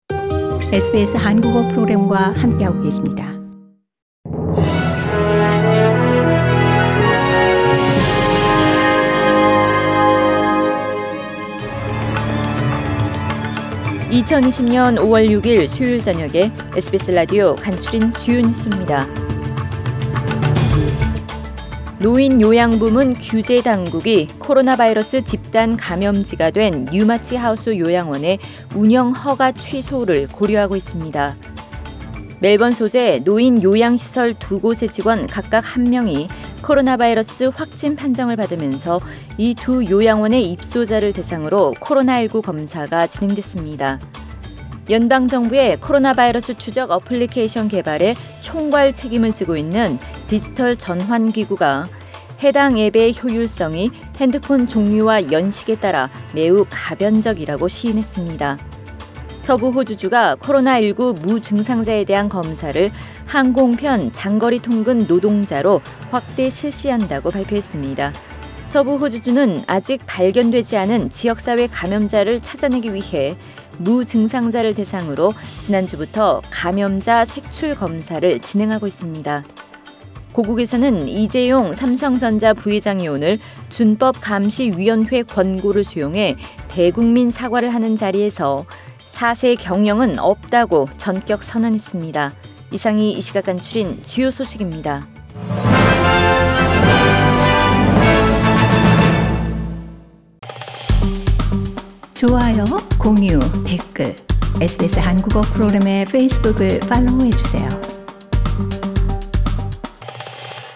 SBS 한국어 뉴스 간추린 주요 소식 – 5월 6일 수요일